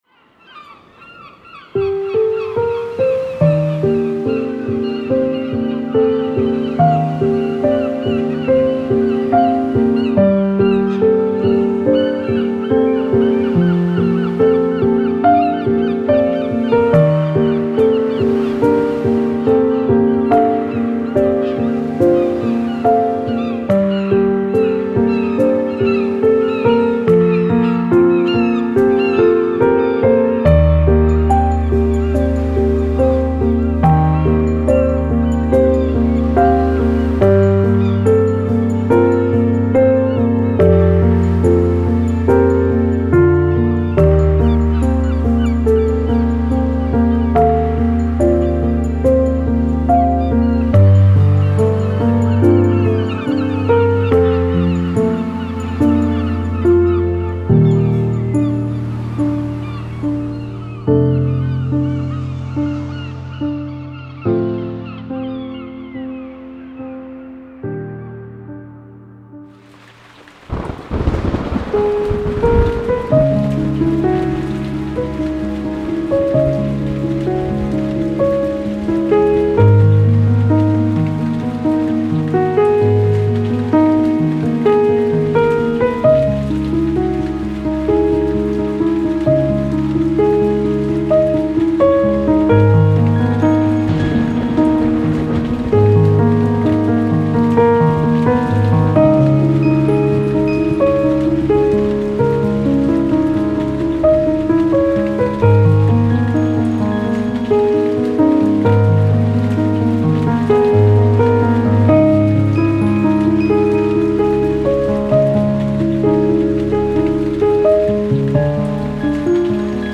Genre:Filmscore
デモに含まれるその他の音はイメージとして使用されており、本パックには含まれておりません。
デモサウンドはコチラ↓
20 Piano Themes
Tempo/BPM 60-126